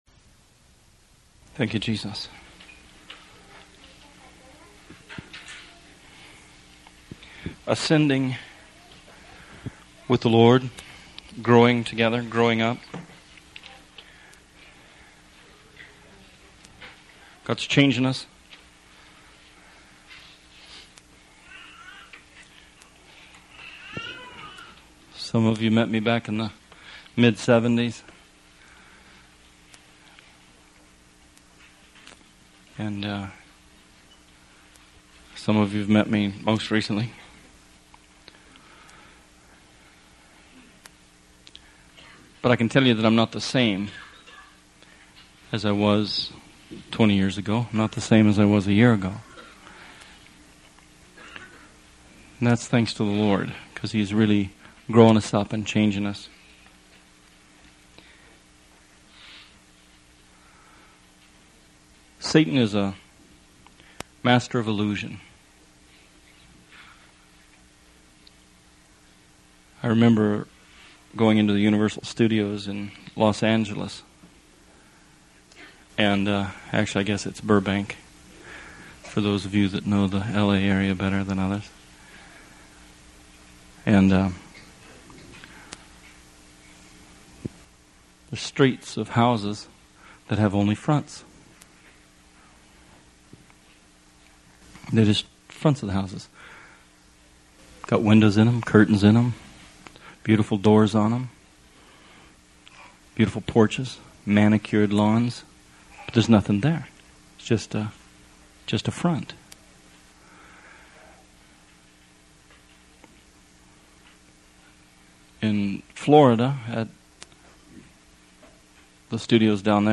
Songs of Degrees: This is a sermon series about Psalms 120 through 134.